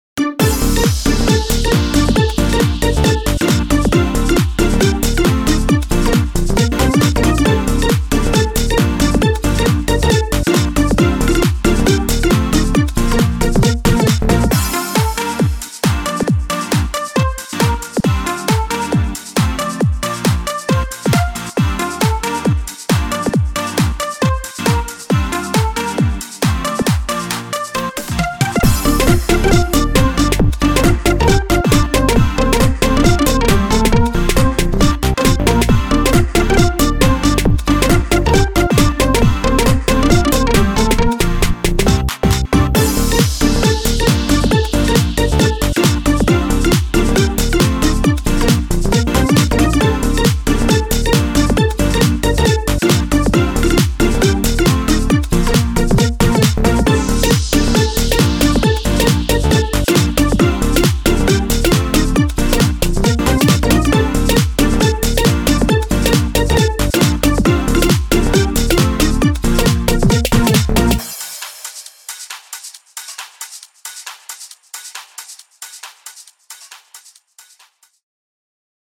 あかるい かわいい